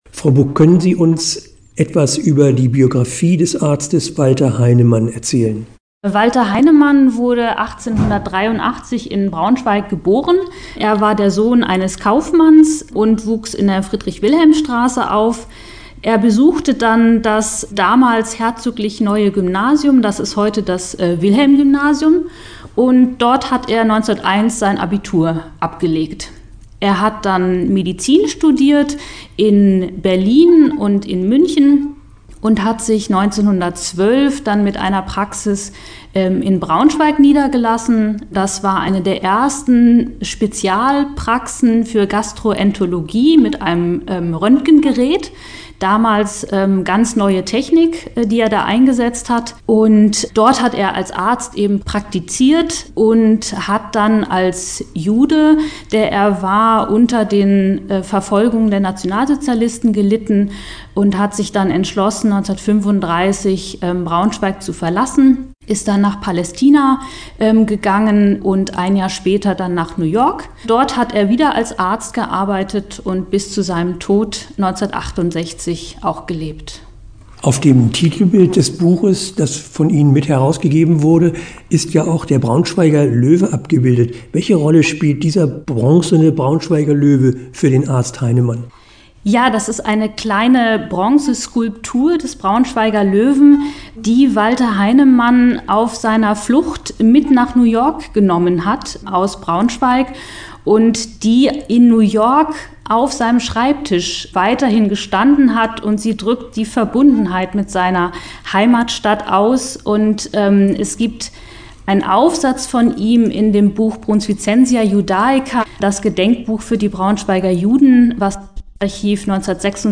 Interview-Heinemann-Memoiren.mp3